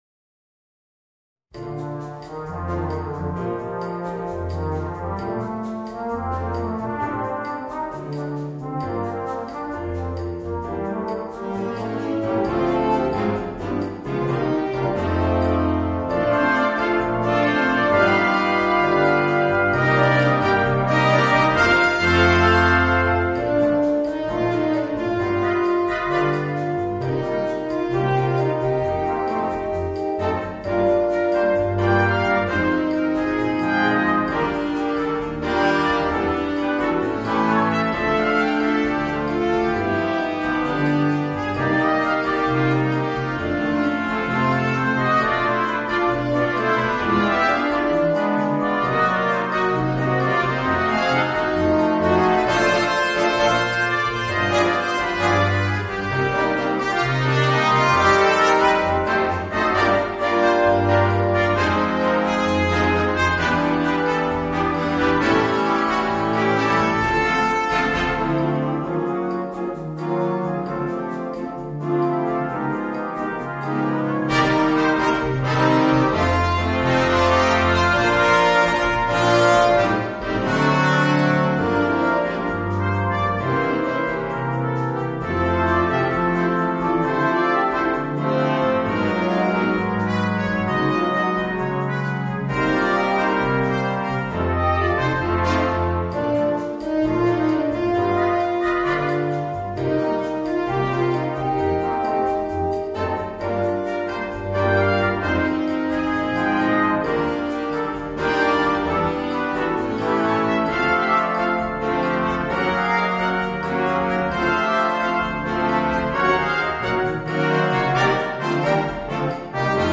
Cha Cha